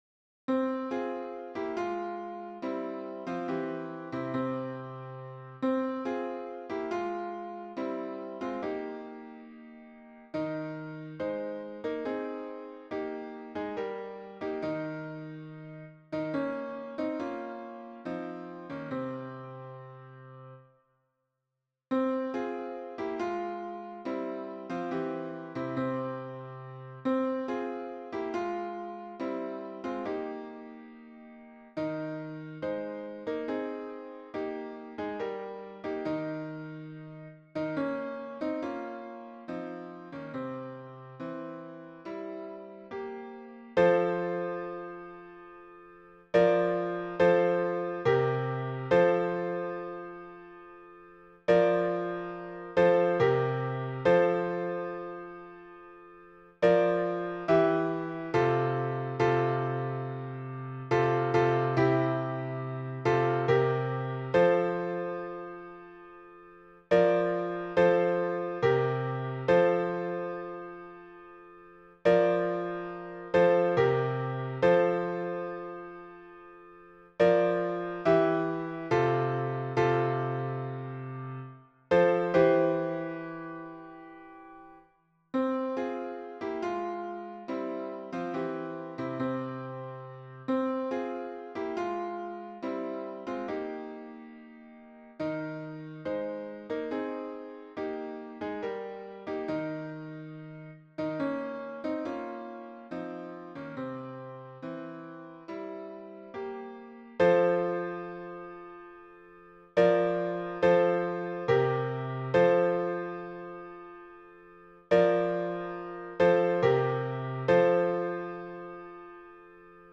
Version pour la chorale à 3 voix - Tutti (version piano)